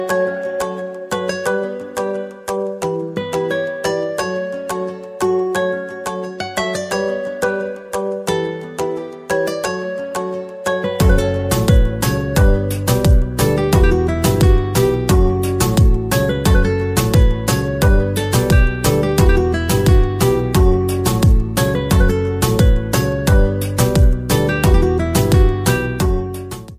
ژانر: بی کلام